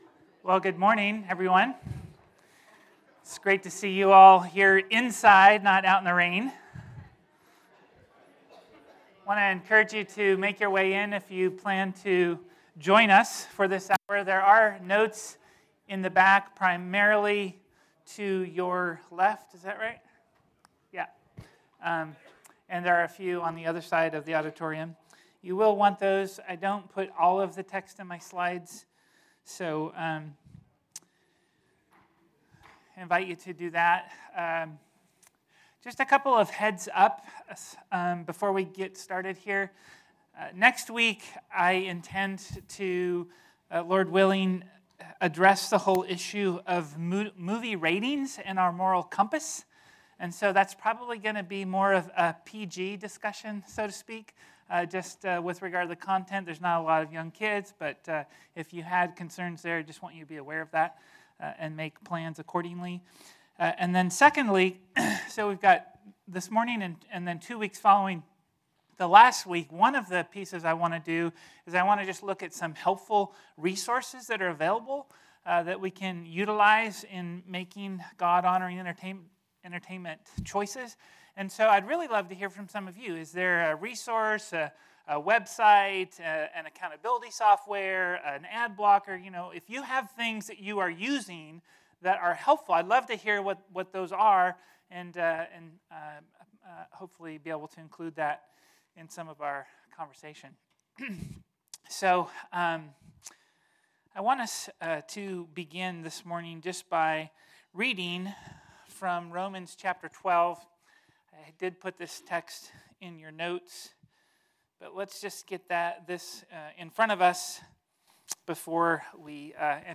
Type: Sunday School